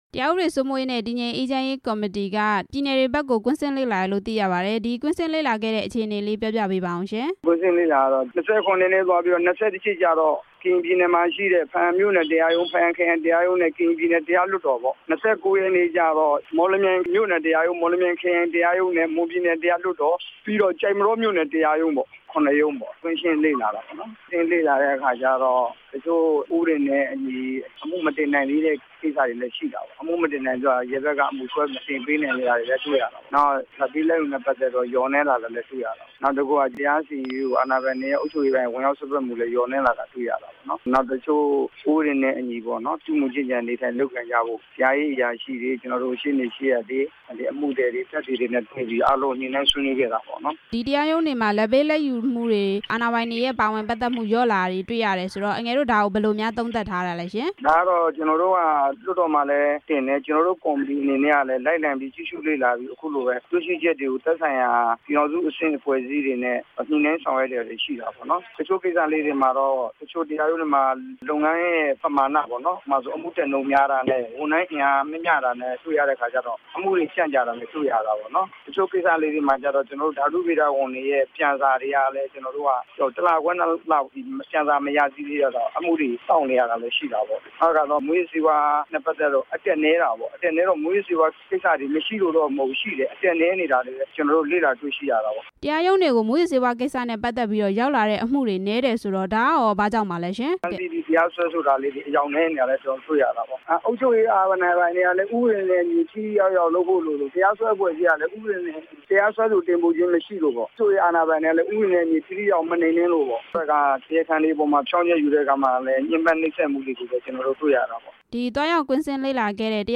ဦးဝင်းမြင့်နဲ့ မေးမြန်းချက်